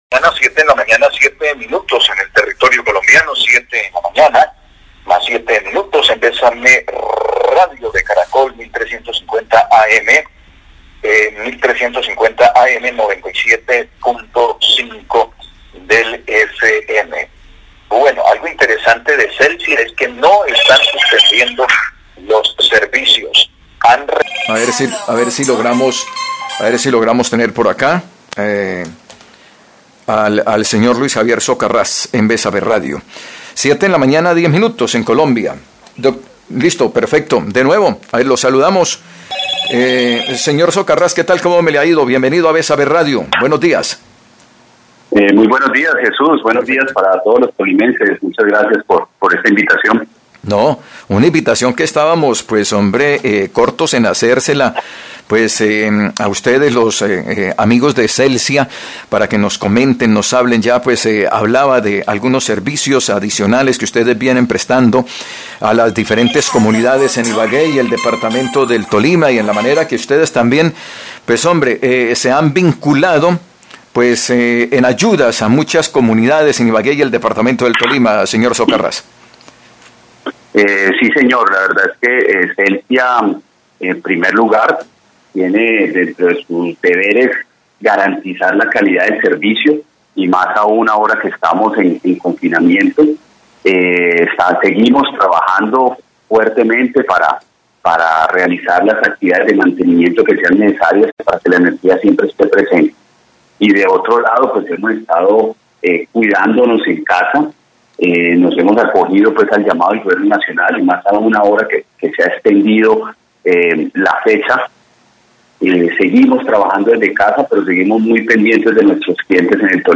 Entrevista
Radio